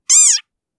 disengage.wav